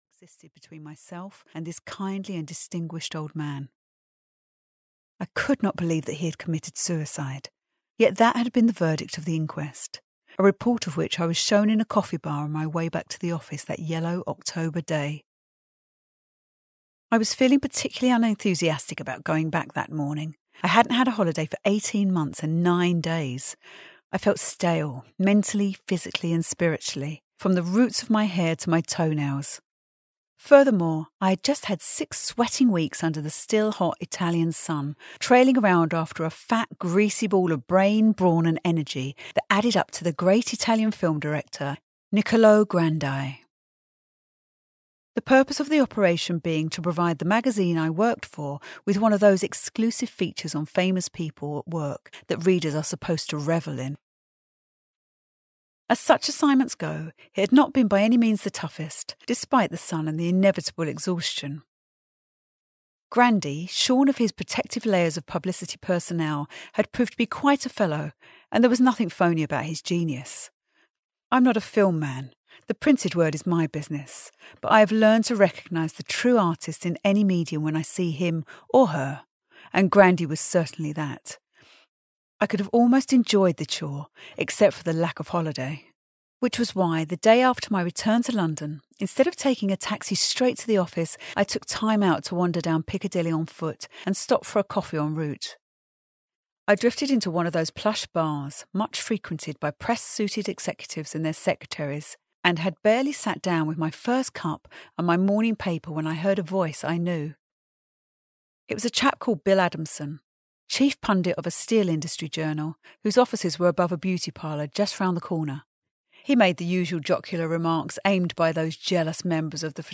Ukázka z knihy
house-of-sand-en-audiokniha